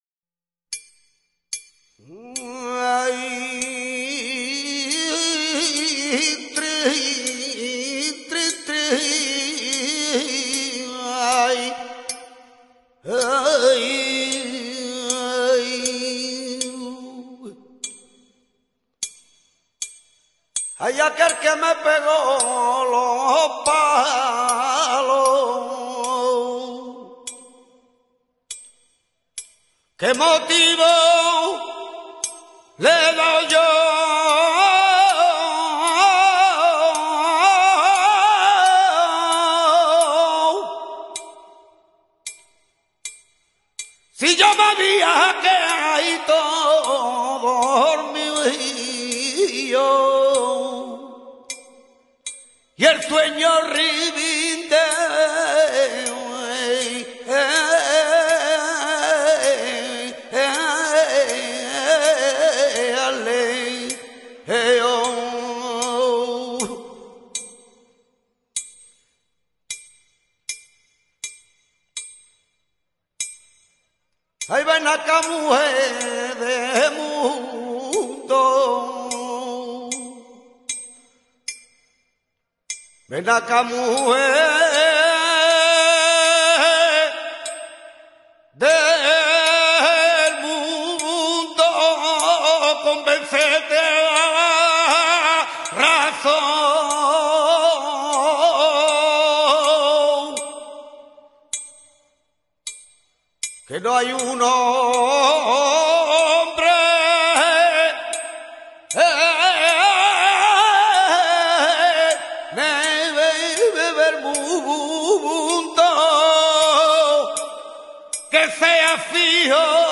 KGnoj6yvMCJ_martinete.mp3